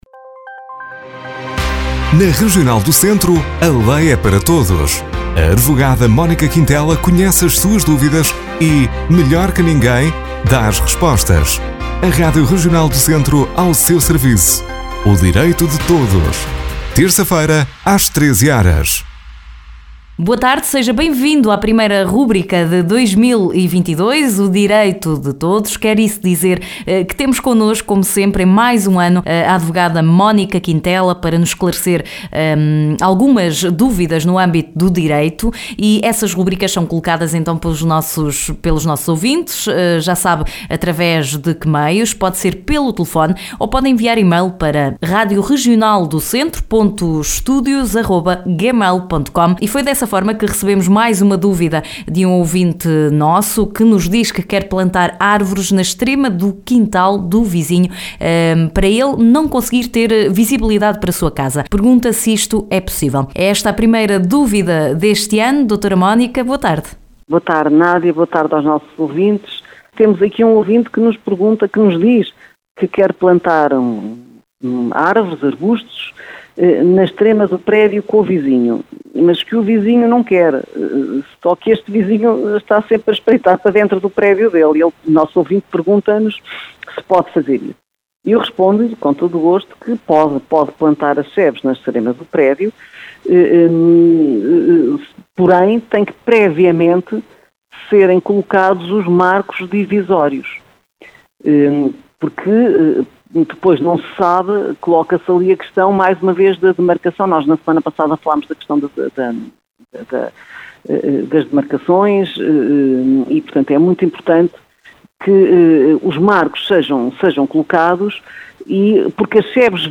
Na rúbrica de hoje a advogada Mónica Quintela responde à dúvida de um ouvinte: quero plantar umas árvores na extrema do quintal do meu vizinho para ele não ver para minha casa… posso?